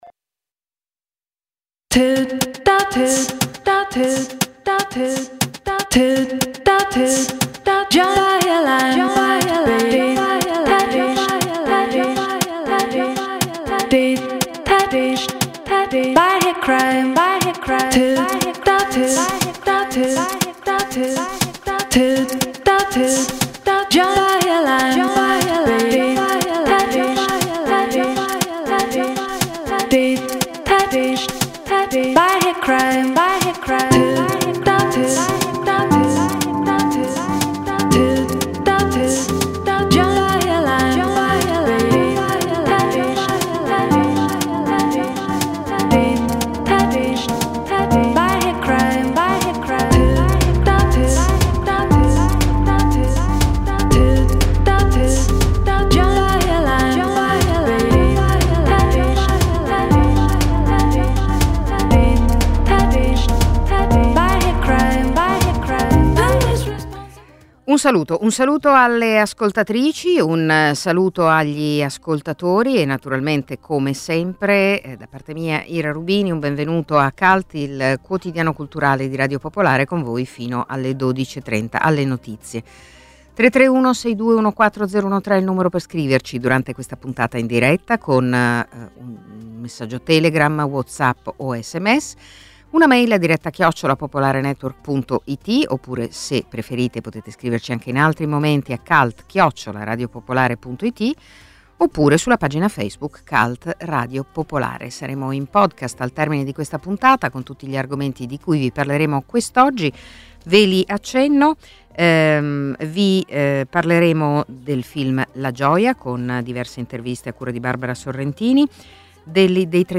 Cult è il quotidiano culturale di Radio Popolare, in onda dal lunedì al venerdì dalle 11.30 alle 12.30.